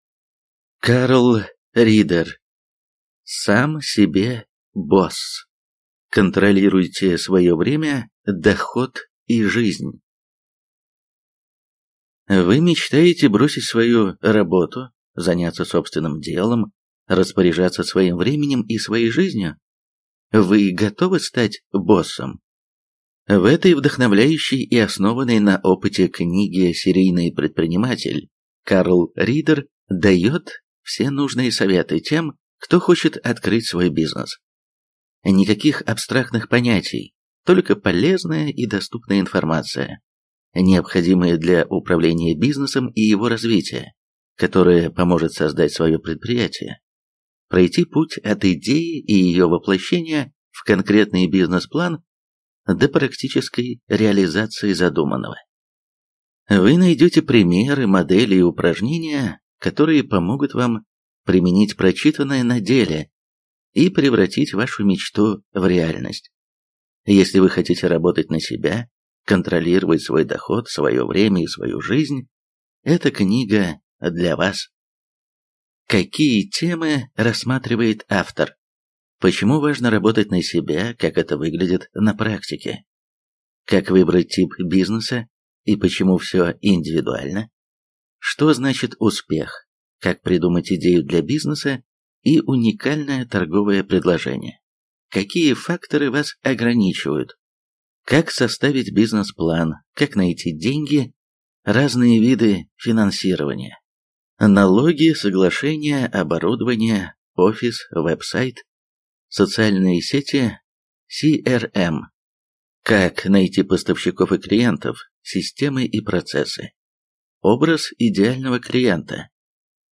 Аудиокнига Сам себе босс. Контролируйте свое время, доход и жизнь | Библиотека аудиокниг